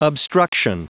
Prononciation du mot obstruction en anglais (fichier audio)
Prononciation du mot : obstruction